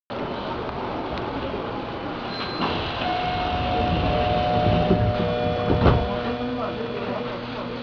・9000形ドアチャイム
【東側】閉扉時（7秒：44.7KB）
開扉時は２回、閉扉時は１回流れます。西側は２打点、東側は３打点となっています。